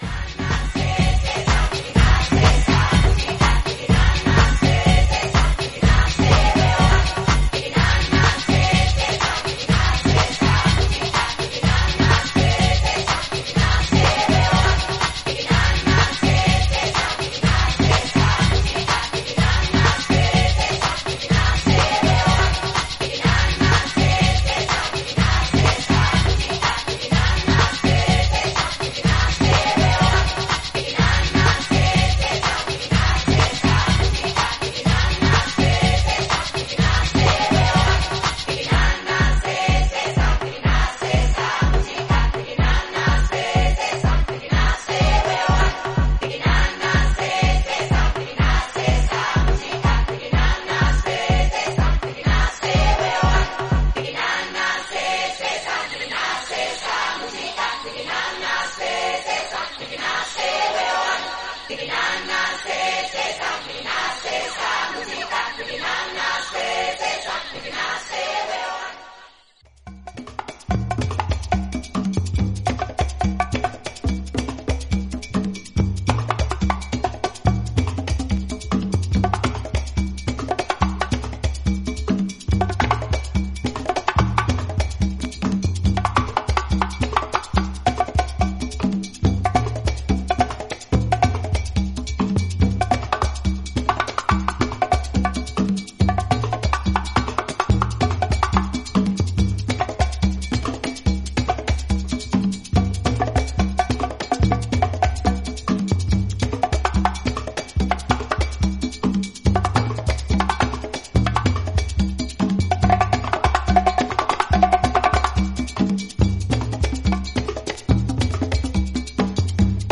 プリミティブなパーカッションのみのグルーヴになる後半の展開もナイスです。